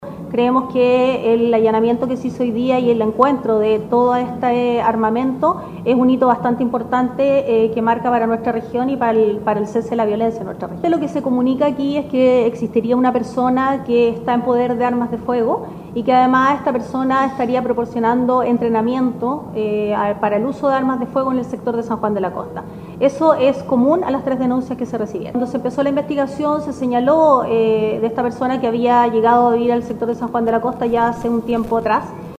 La fiscal indicó que no hay relación entre este allanamiento y el operativo desarrollado hace algunas semanas en el sector de Loma de la Piedra. Igualmente, señaló que se investiga el desarrollo de un presunto entrenamiento en el uso de armas de fuego.